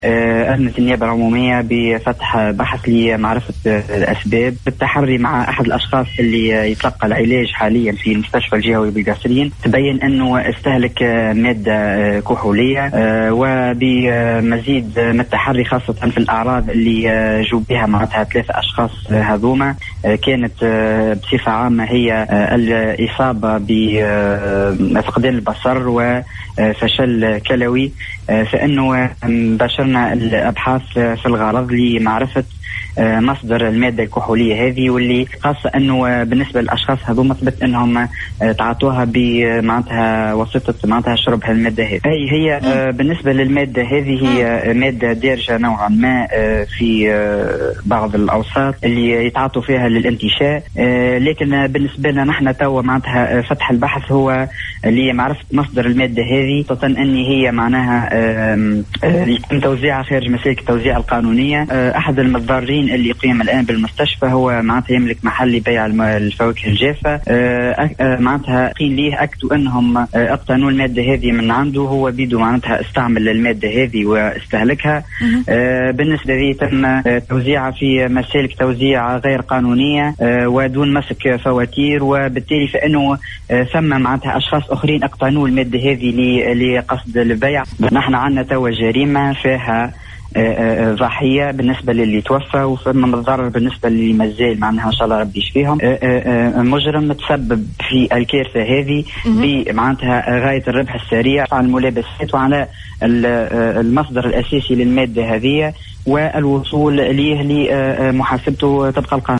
اكد مساعد وكيل الجمهورية بالمحكمة الابتدائية بالقصرين  شوقي بوعزي  خلال مداخلته براديو السيليوم اف ام  ان النيابة العمومية بالمحكمة الإبتدائية بالقصرين اذنت امس  الأحد 07 فيفري 2021، بفتح بحث تحقيقي في حادثة هلاك اشخاص جراء تناولهم مادة سامة يشتبه بانها مادة “القوارص”  وعرض جثثهم على الطبيب الشرعي بالمستشفى الجهوي بالقصرين للتعرف على نوع المادة التي تسببت في وفاتهم،